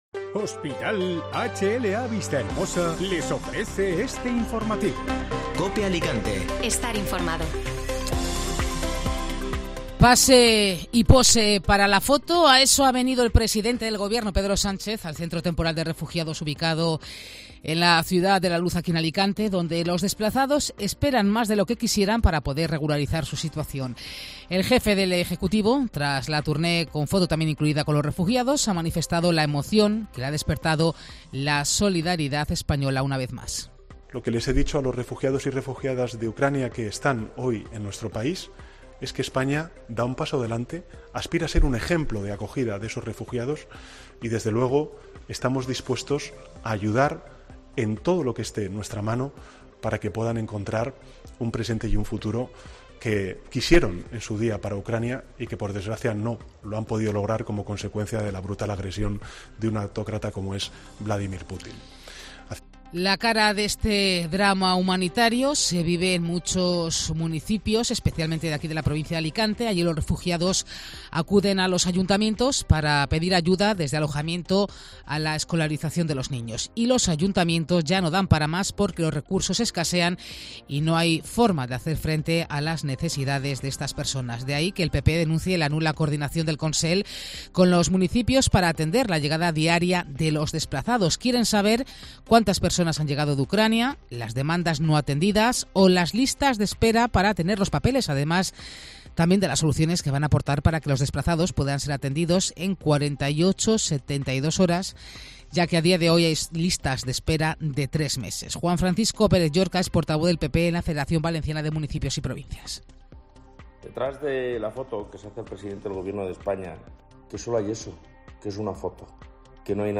Informativo Mediodía COPE (Jueves 31 de marzo)